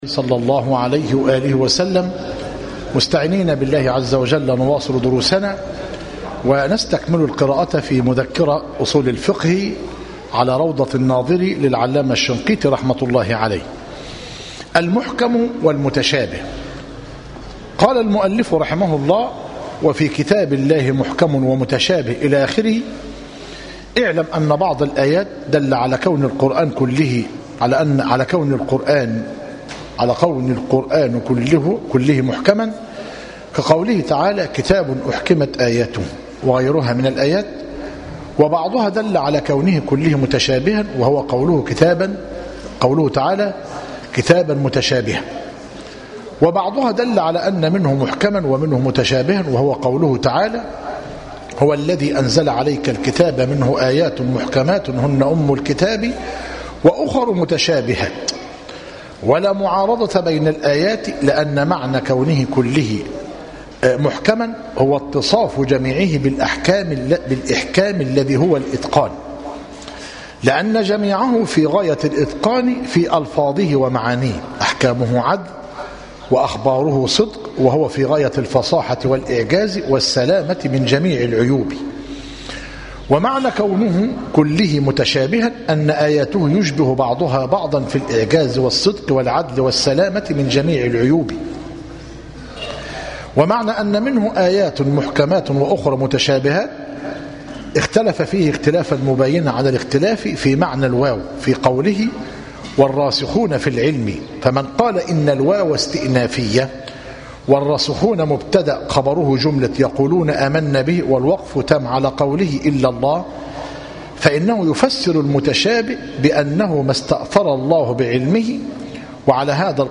مذكرة أصول الفقه للعلامة الشنقيطي رحمه الله - مسجد التوحيد - ميت الرخا - زفتى - غربية - المحاضرة الحادية والعشرون- بتاريخ 2 - صفر - 1437هـ الموافق 14 - نوفمبر- 2015 م